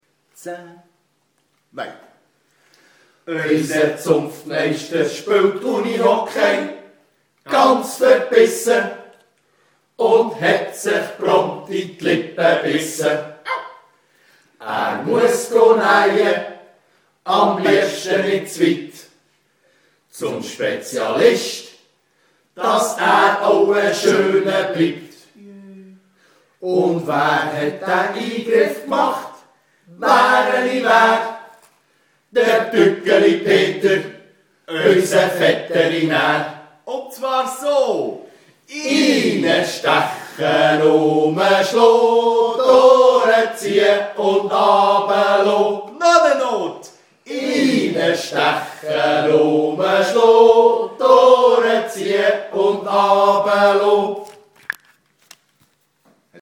Fasnacht 2011